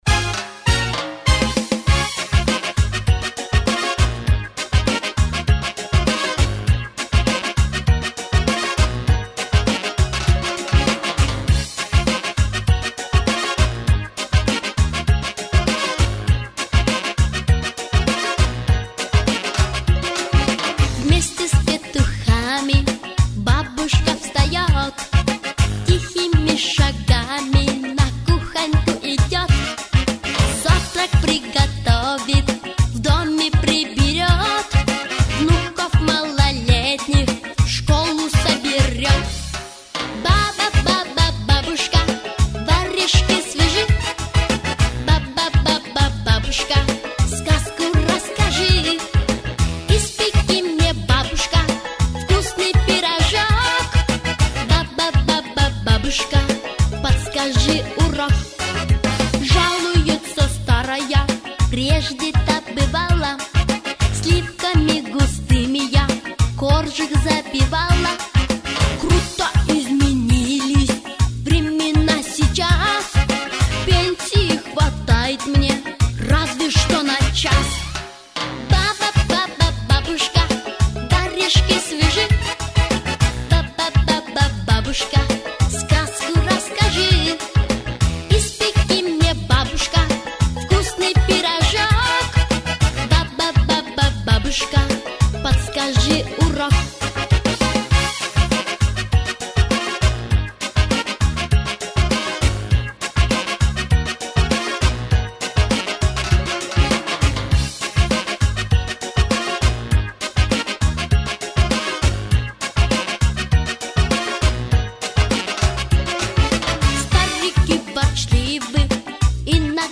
Детские песни и музыка